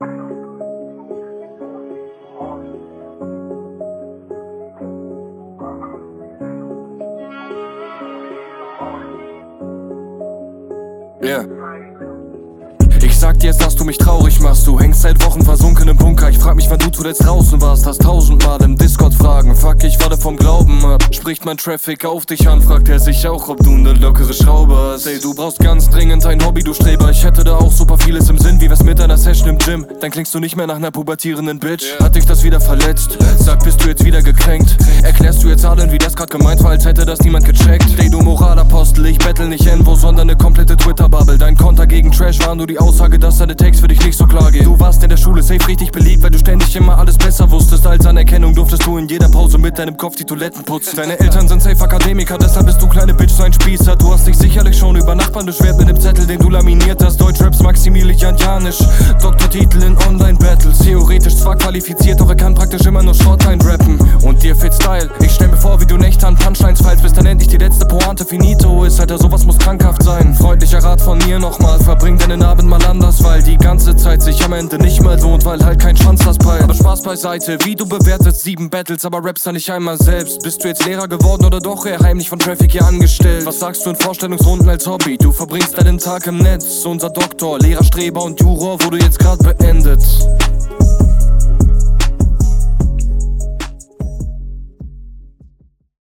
Stetiger Gegnerbezug in den Kontern, sehr cool gerappt wie immer, ziemlich gut zurückgeschlagen, der Gegner …